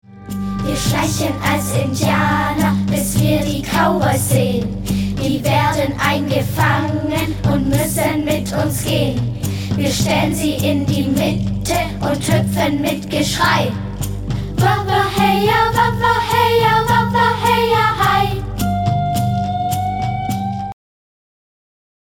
Gattung: Sing- und Sprechkanons für jede Gelegenheit
Besetzung: Gesang Noten